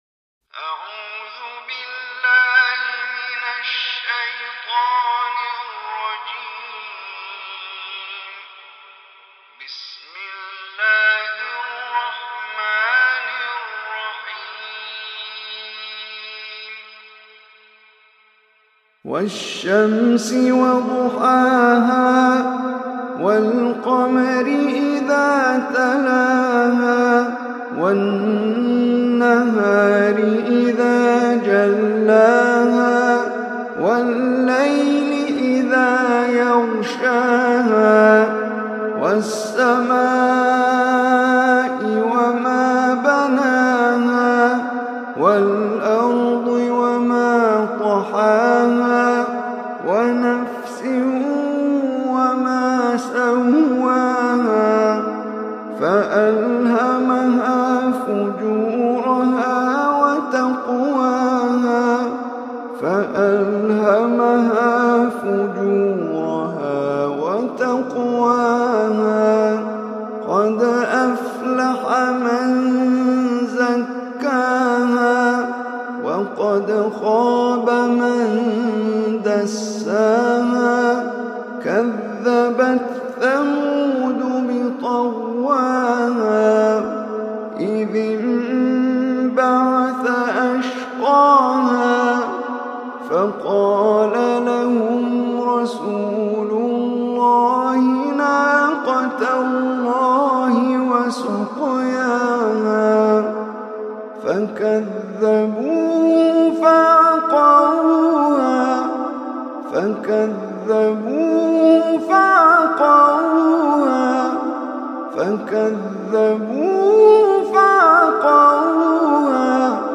Surah Shams Recitation by Omar Hisham Arabi
Surah Shams, is 91 surah of Holy Quran. Listen or play online mp3 tilawat / recitation in Arabic in the beautiful voice of Omar Hisham Al Arabi.